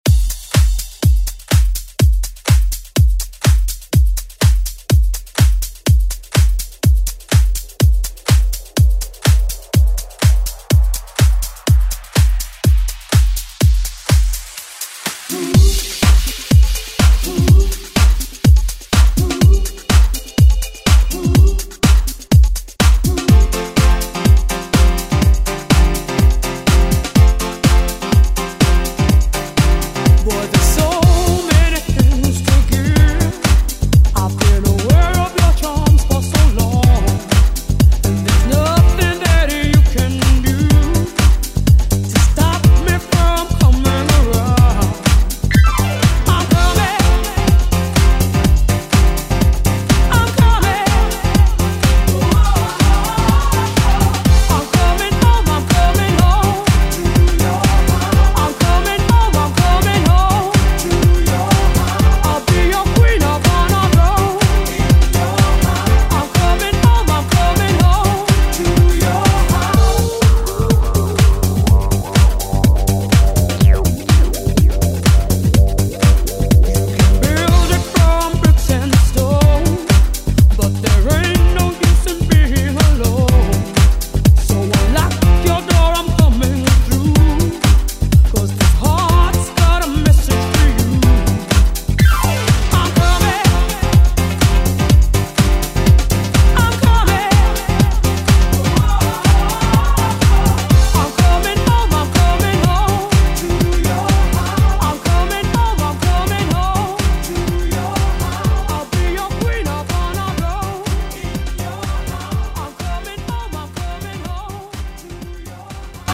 Electronic House Music Extended ReDrum Clean 124 bpm
BPM: 124 Time